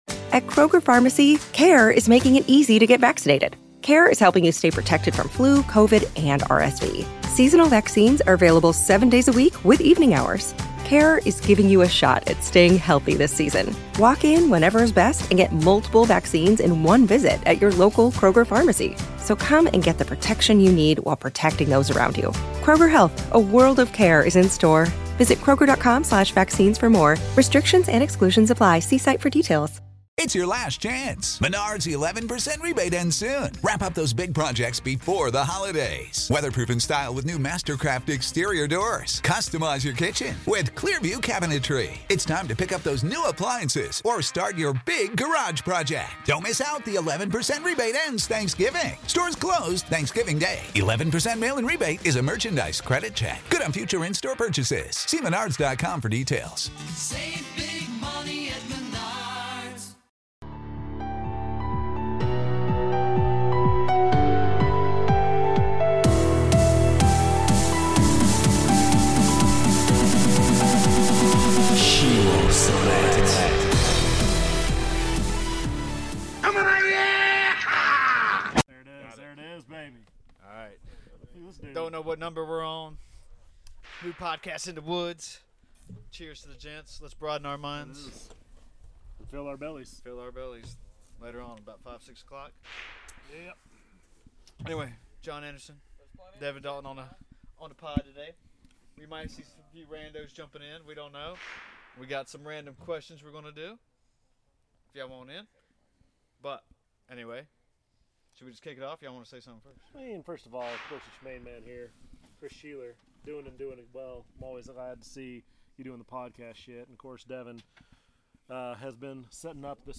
Join us for a relaxed, thought-provoking episode recorded under the open sky in Southwest Virginia near the Appalachian Mountains. In this special edition, we take "Thinking and Drinking" to a new level—literally outdoors, surrounded by nature, and accompanied by good drinks. We dive into a series of random, conversation-sparking questions from the popular game, "Thinking and Drinking."